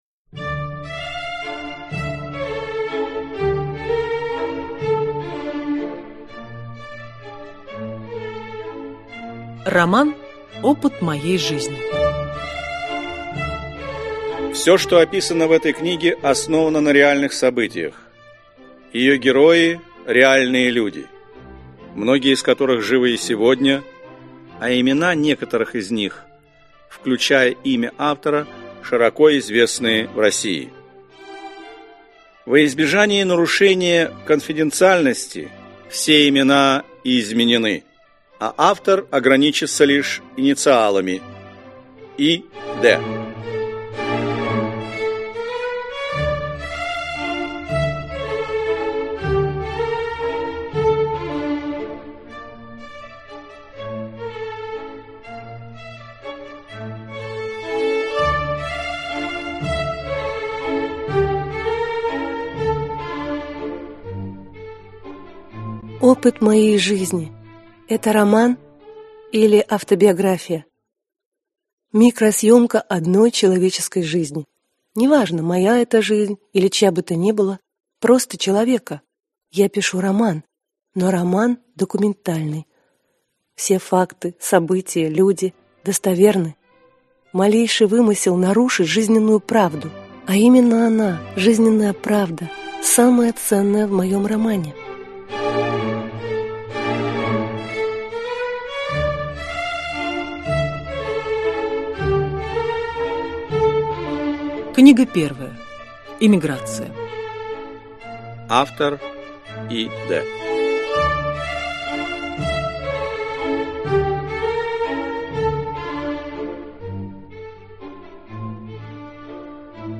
Аудиокнига Опыт моей жизни. Книга 1. Эмиграция. Приезд в США | Библиотека аудиокниг